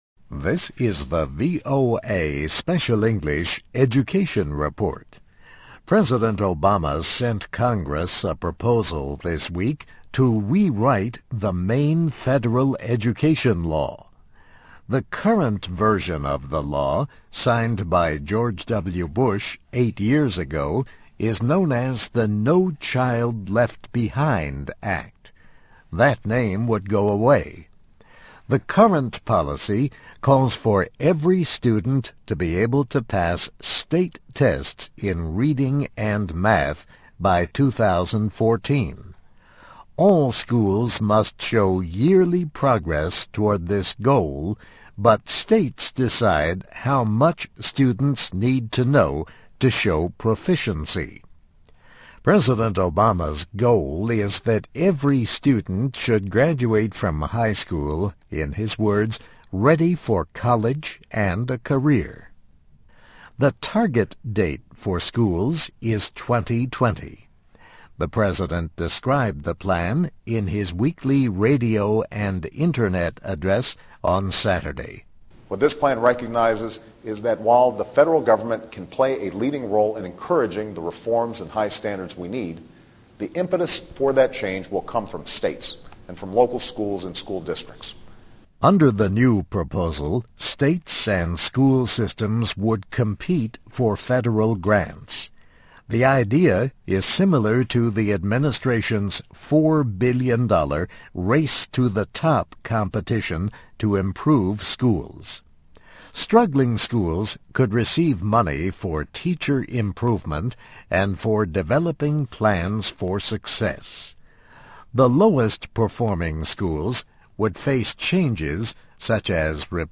VOA Special English, Education Report, Obama's 'Blueprint for Reform' in Education Goes to Congress